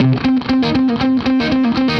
Index of /musicradar/80s-heat-samples/120bpm
AM_HeroGuitar_120-C01.wav